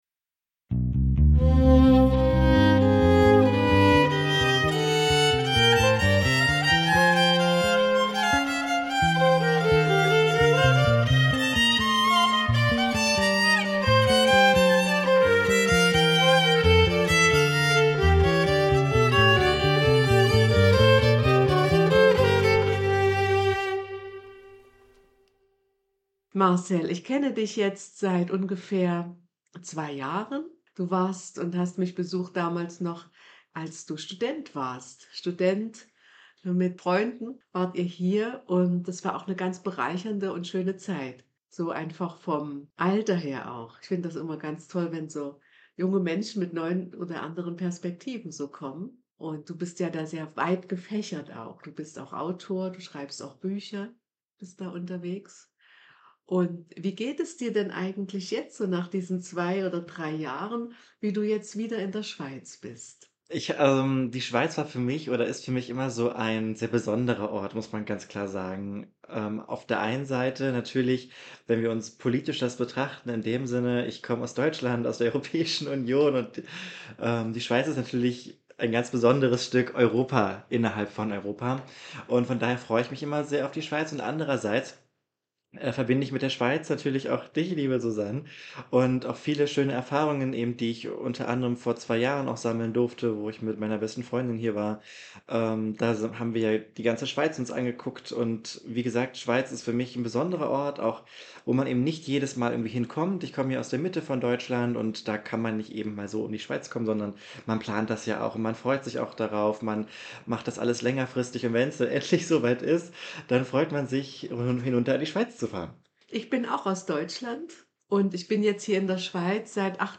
In diesem Gespräch geht es um Perspektiven und Blickwinkel, das Unterwegssein sowie das Weggehen und Ankommen in Zeiten von Freiheit und Flucht.